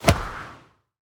throw-projectile-1.ogg